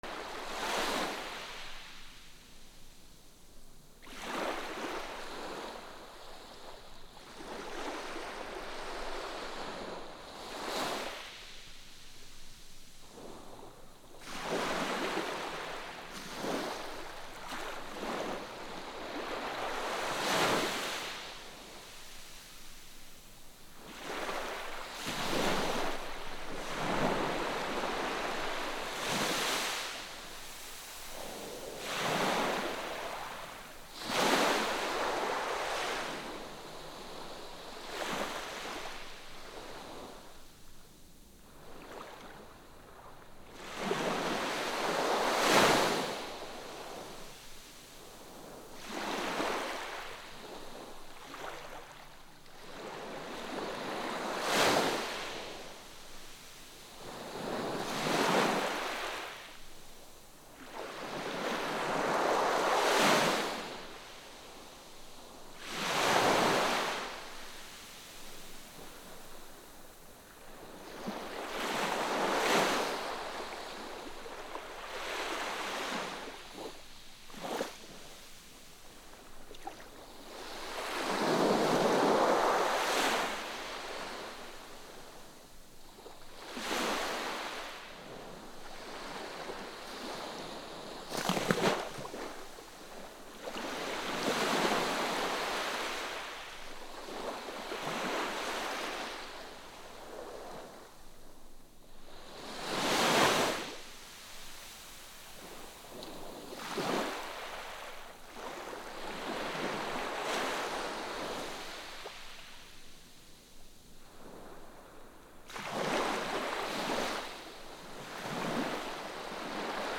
砂浜波 波打ち際ぎりぎり 穏やか
/ B｜環境音(自然) / B-10 ｜波の音 / 波の音
サー 原音あり NT4